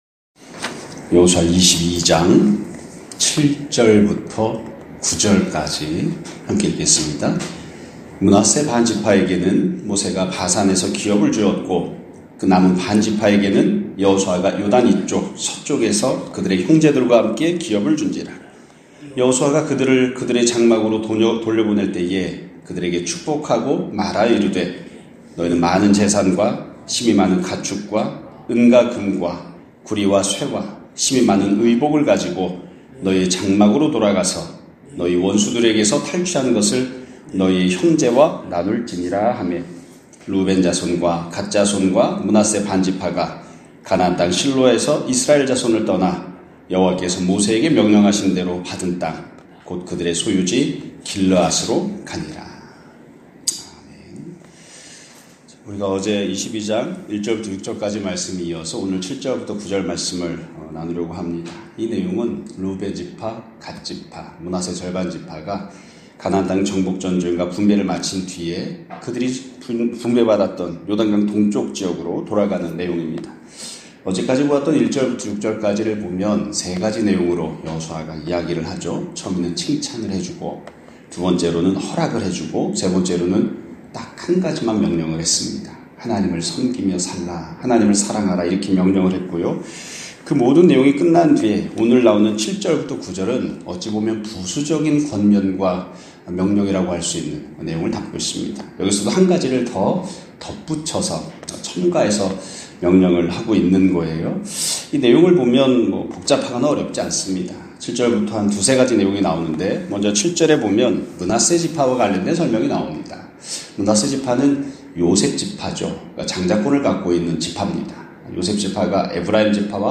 2025년 2월 7일(금 요일) <아침예배> 설교입니다.